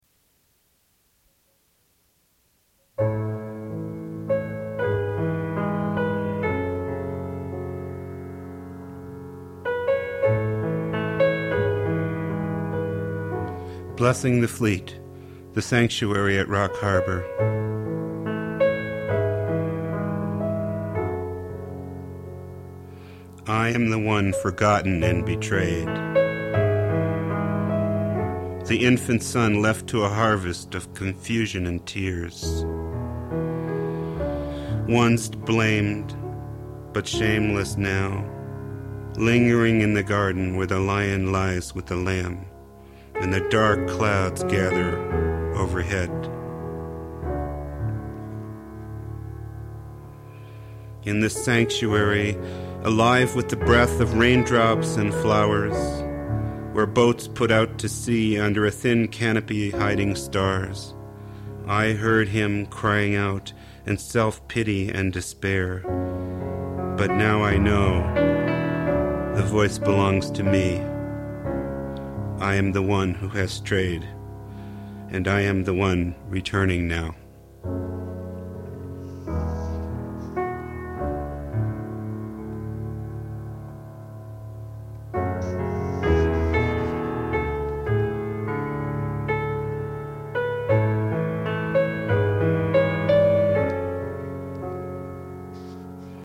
with gorgeous piano accompaniment